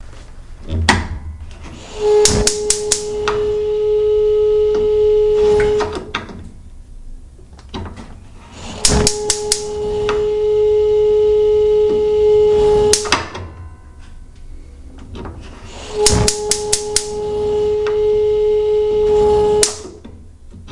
随机 " 河流 小溪流运行 高细节运动
描述：河小流运行高细节运动.flac
标签： 小溪 河流 运行时
声道立体声